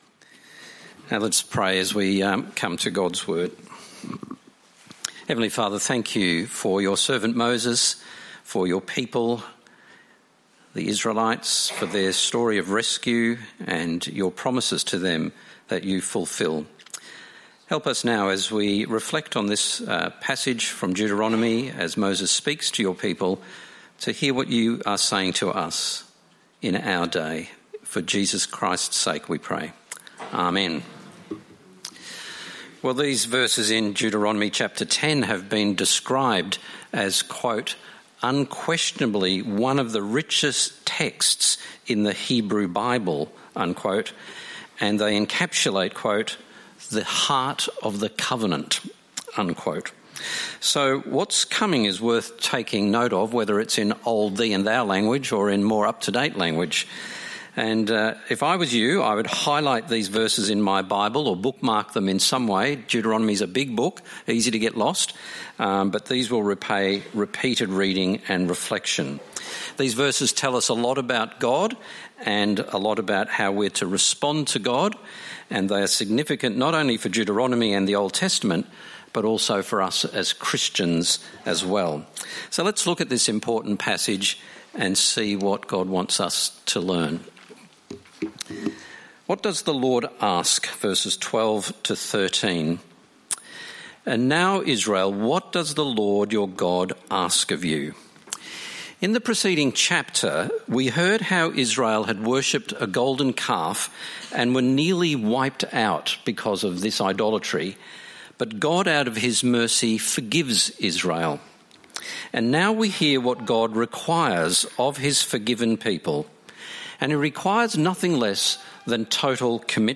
Download Download Bible Passage Deuteronomy 10:12-22 In this sermon